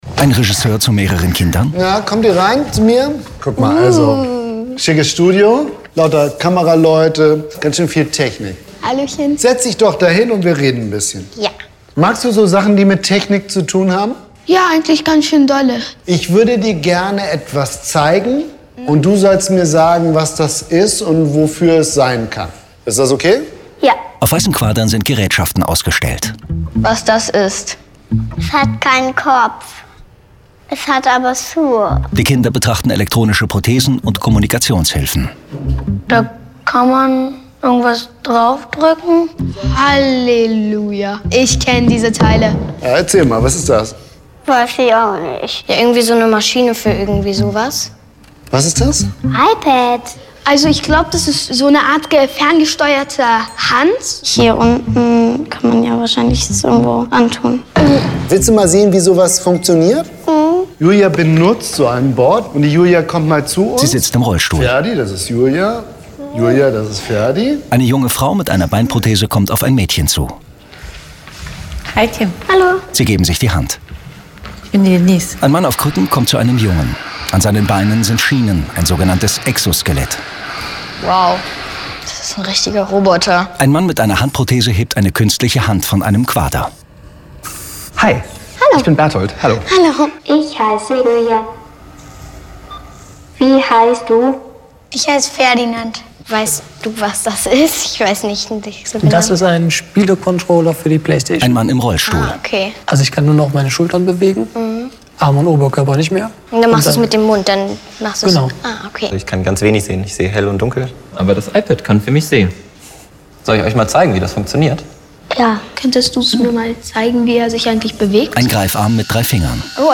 aktion-mensch_beispiel_audioDescription.mp3